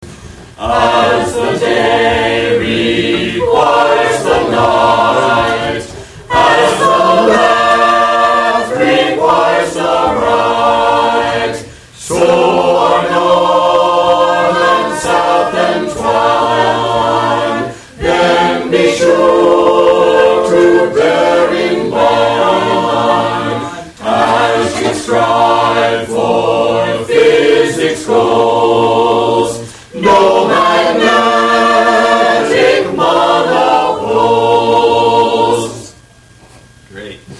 Recording (As performed by the choir of St. James United Church of Christ, Havertown PA):